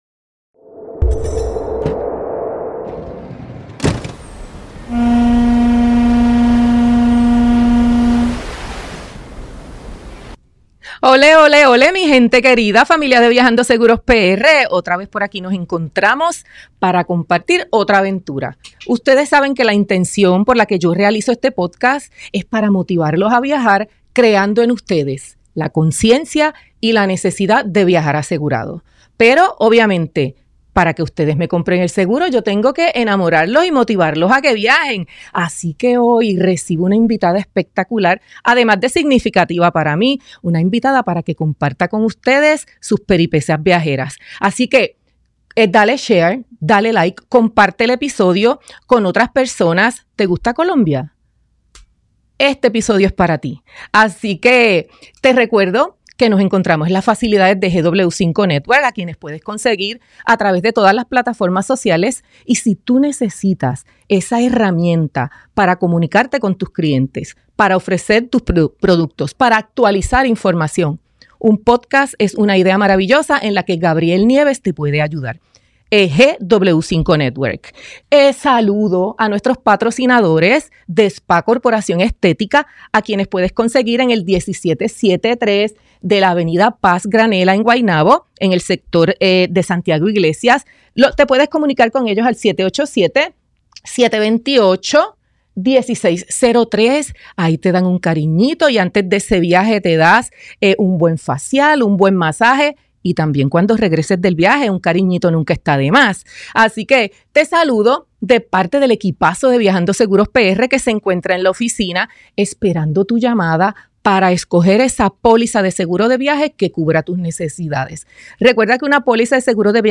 Descubre sus recomendaciones de seguridad y escucha sobre sus próximos proyectos y sueños futuros. ¡Una conversación inspiradora que no te puedes perder!